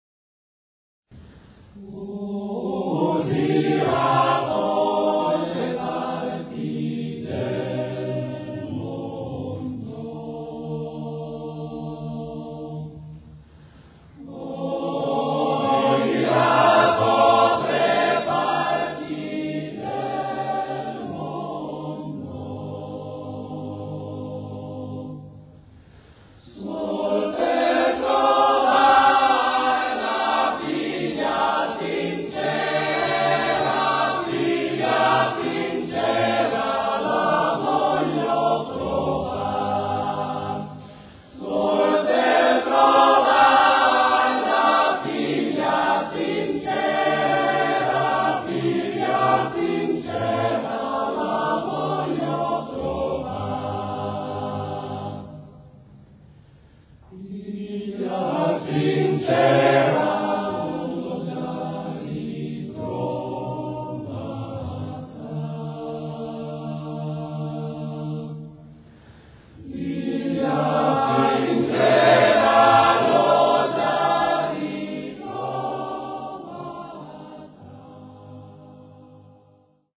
Ricerca, elaborazione, esecuzione di canti popolari emiliani
voci virili